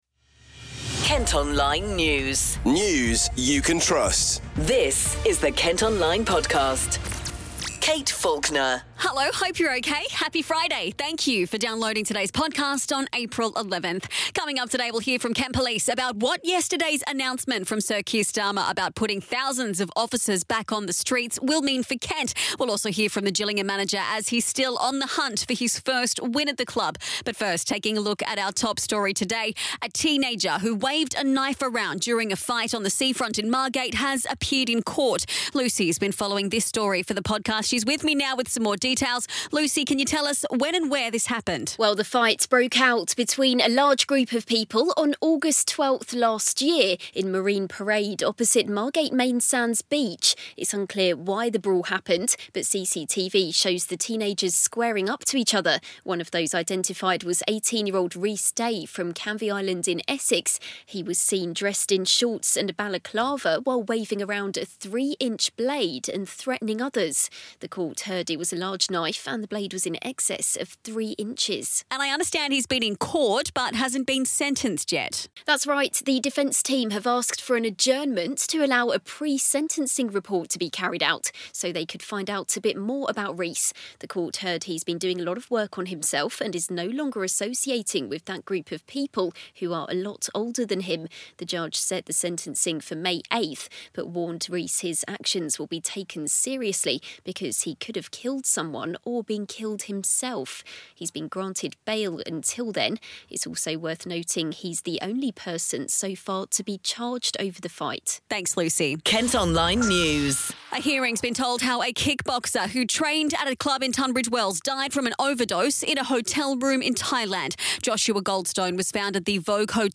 Also in today’s podcast, you can hear from Kent Police following an announcement named bobbies are returning to town centres across the county in a major shakeup in policing.